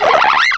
cry_not_dewott.aif